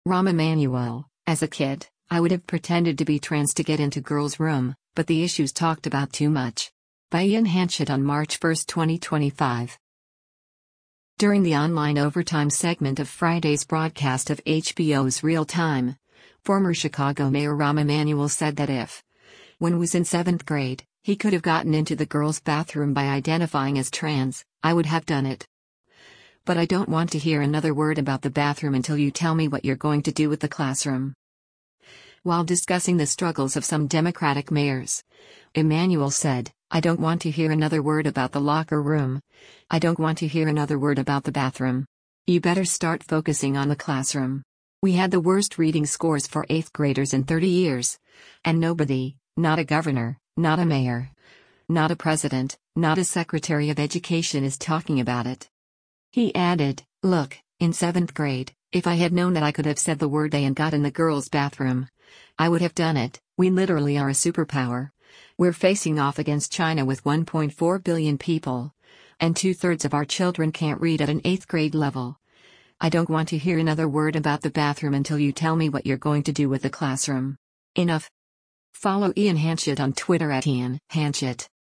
During the online “Overtime” segment of Friday’s broadcast of HBO’s “Real Time,” former Chicago Mayor Rahm Emanuel said that if, when was in 7th grade, he could have gotten into the girl’s bathroom by identifying as trans, “I would have done it.”